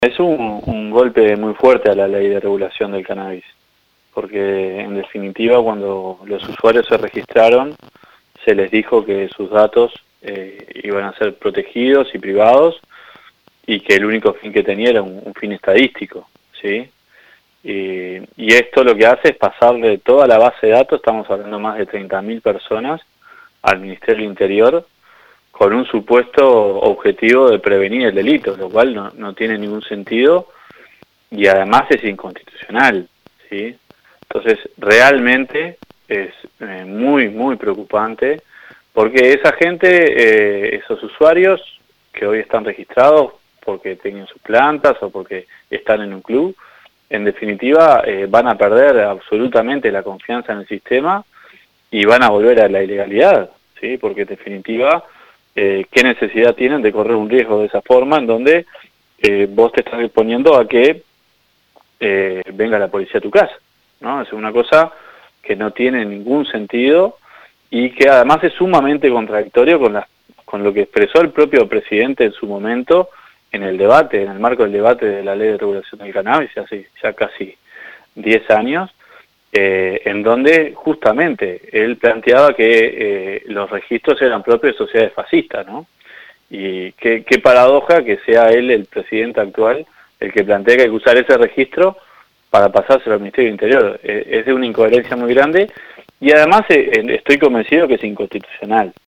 «Lacalle Pou opinaba que los registros de cannabis eran propios de sociedades fascistas», dijo el senador del Frente Amplio Sebastián Sabini a 970 Noticias.